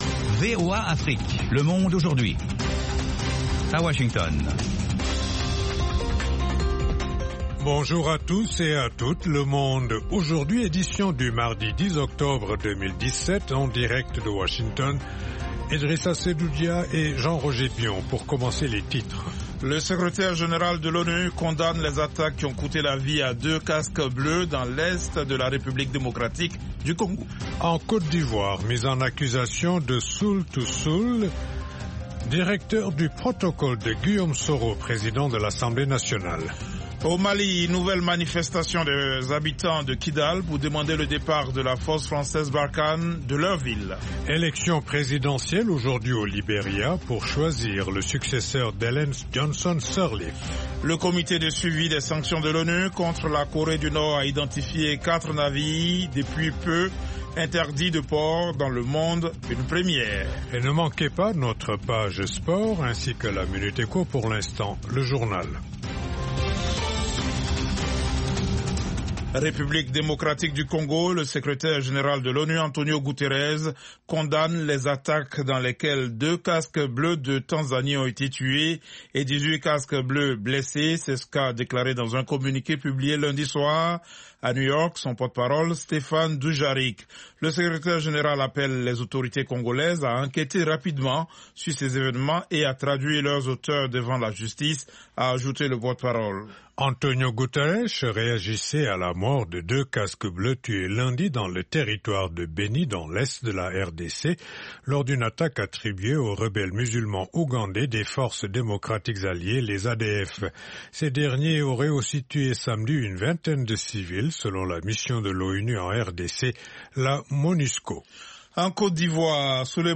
Le Monde Aujourd'hui, édition pour l'Afrique de l'Ouest, une information de proximité pour mieux aborder les préoccupations de nos auditeurs en Afrique de l’Ouest. Toute l’actualité sous-régionale sous la forme de reportages et d’interviews.